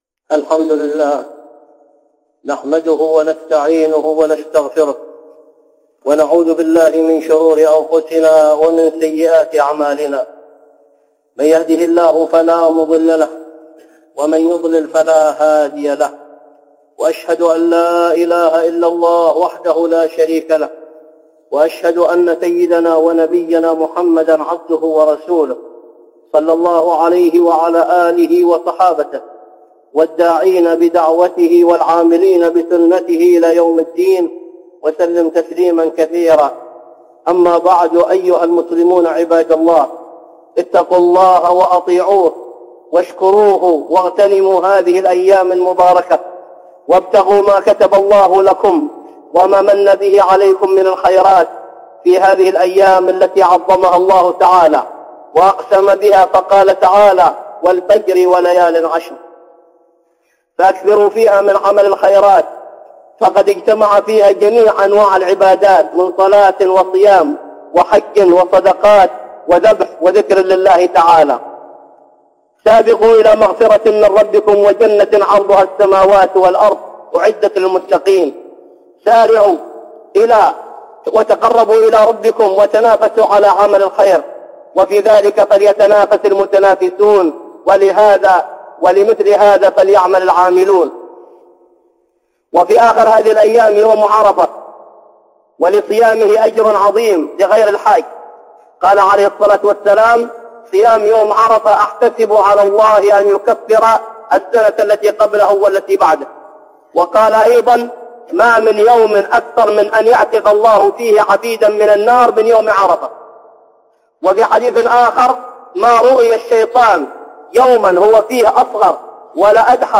(خطبة جمعة) الأضحية وفضل عشر ذي الحجة